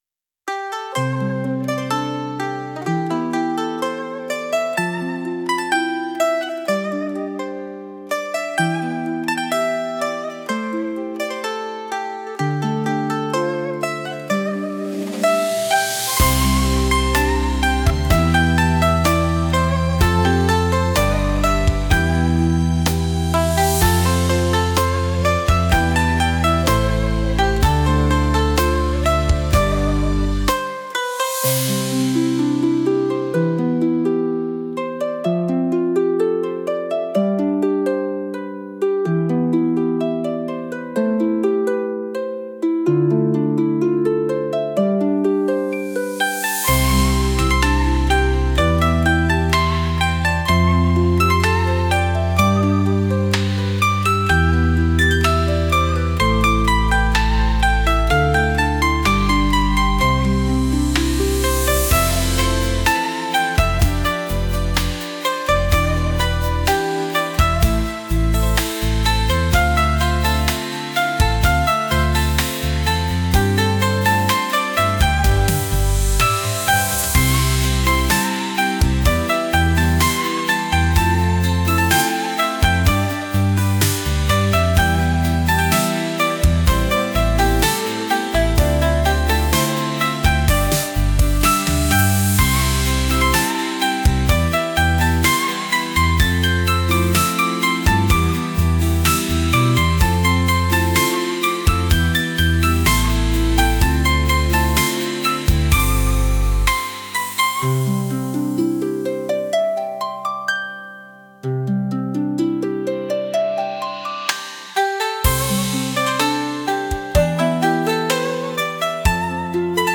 何気ない日常を大切にするためのBGM